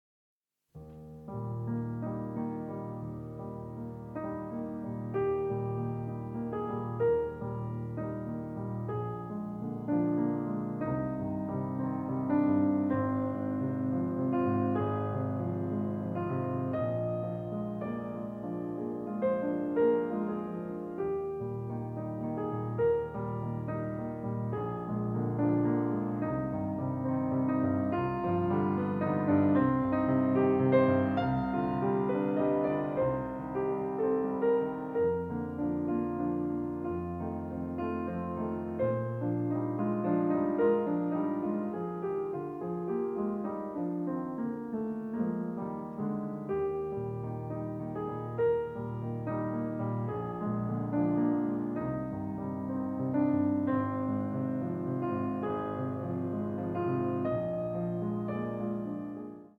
original motion picture soundtrack
Piano